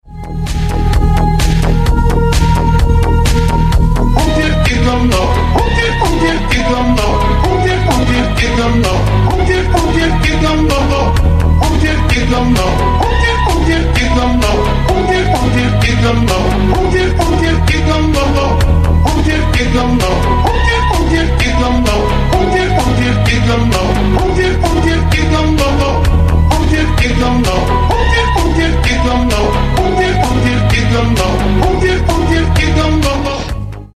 Рингтоны Ремиксы